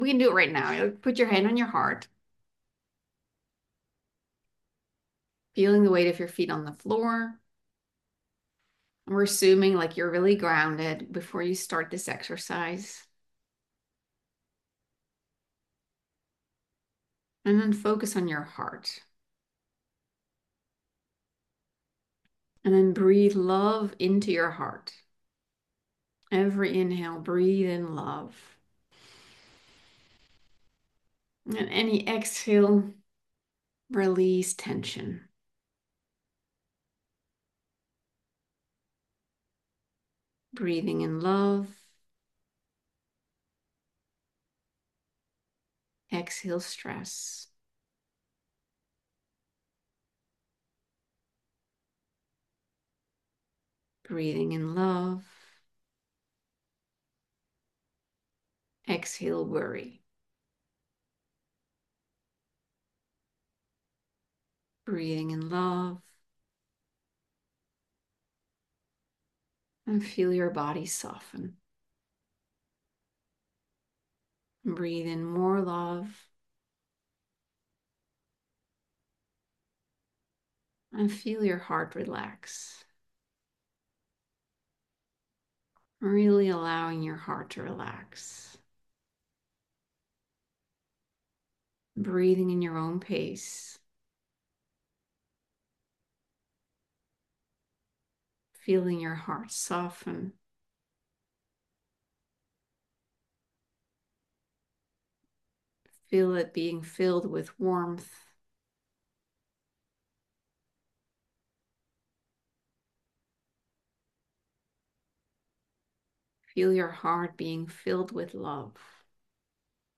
You can try it right now with this 5 min. guided activation.